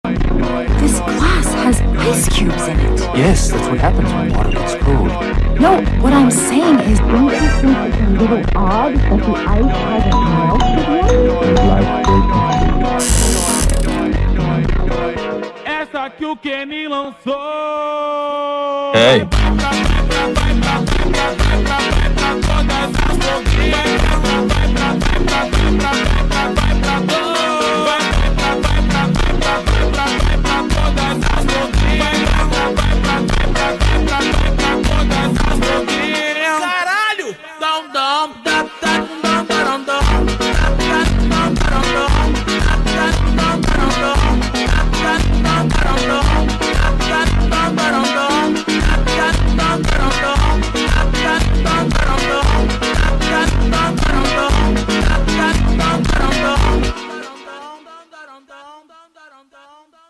slowed & reverb